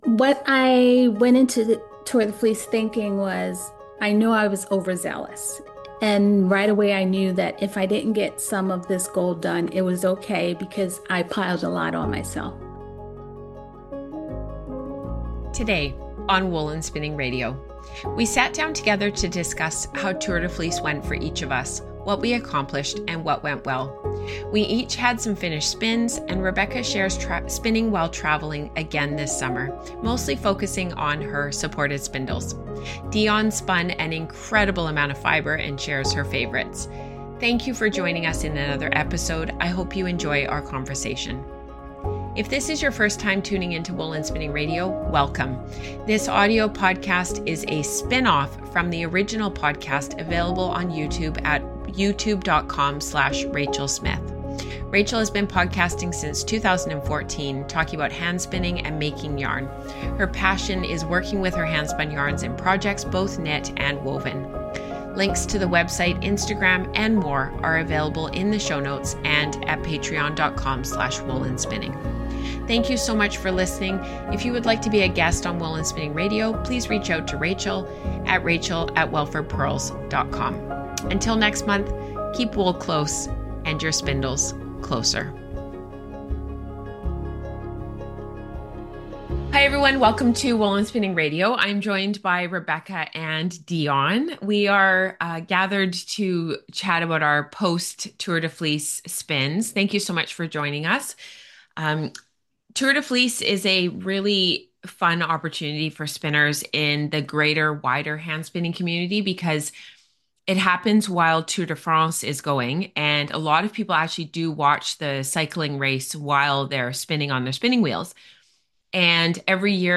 I hope you enjoy our conversation.